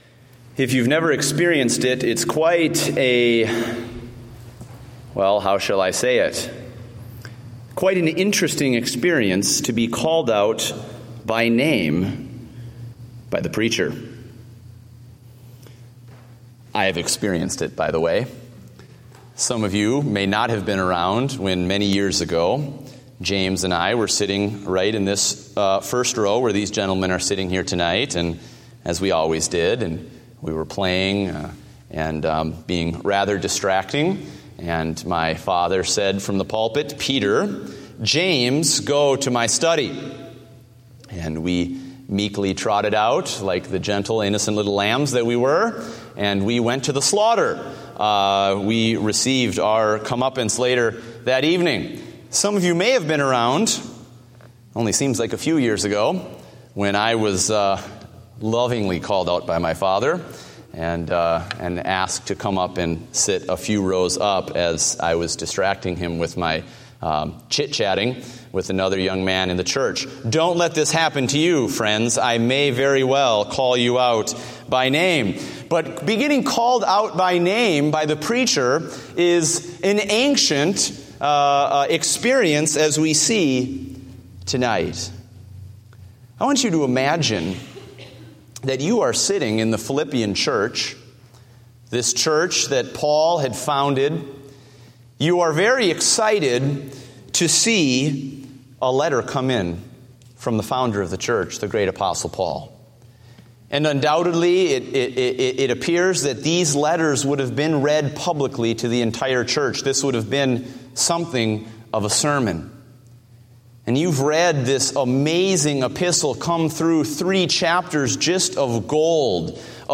Date: August 9, 2015 (Evening Service)